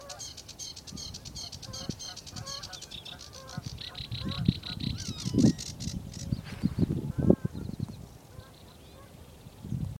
Carricerín Común (Acrocephalus schoenobaenus)
Nombre en inglés: Sedge Warbler
Condición: Silvestre
Certeza: Fotografiada, Vocalización Grabada